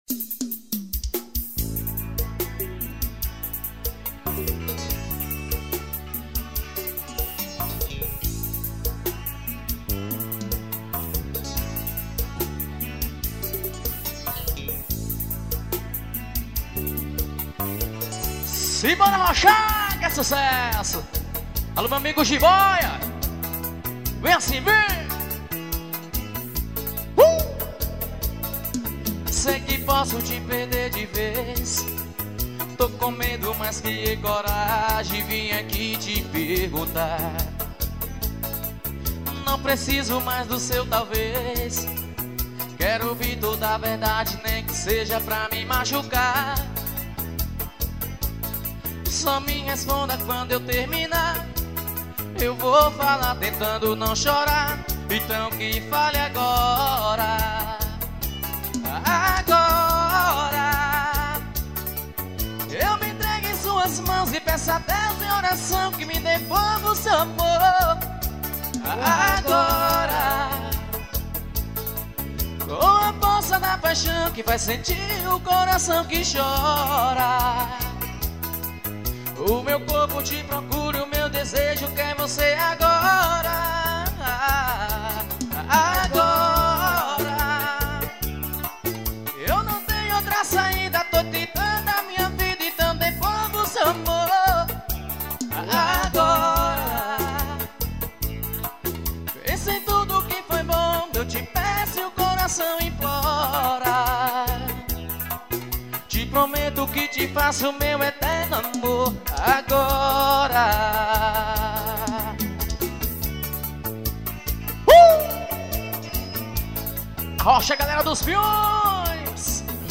Ao vivo nos piões.